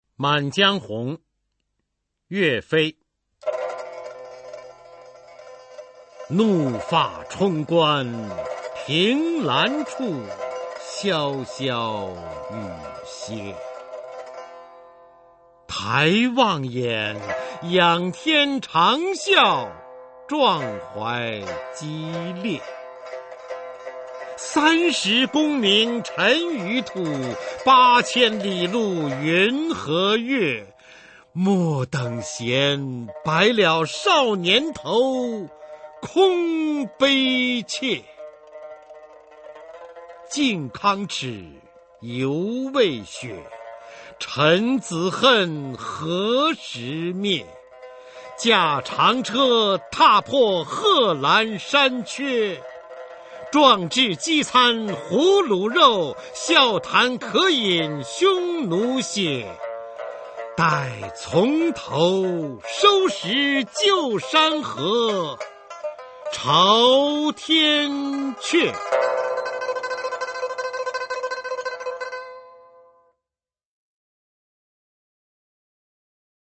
岳飞-满江红2 宋词朗诵